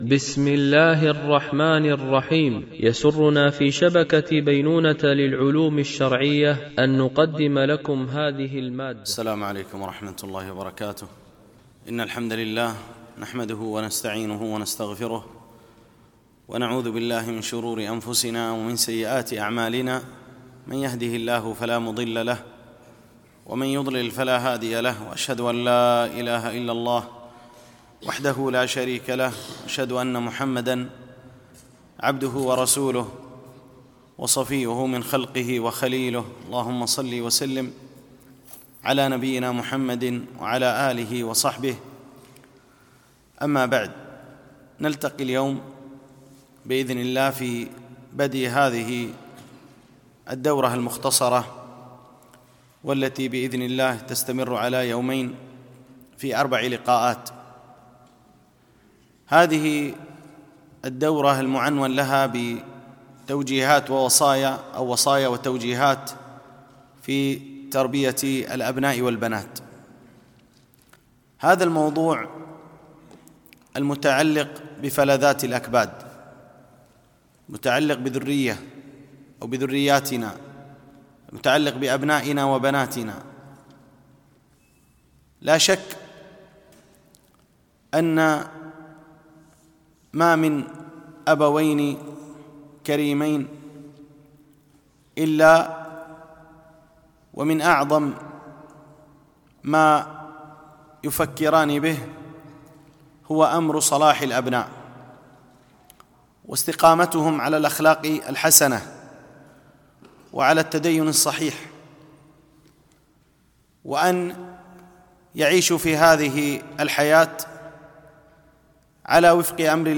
بمسجد عائشة أم المؤمنين - دبي (القوز 4)
MP3 Mono 44kHz 64Kbps (CBR)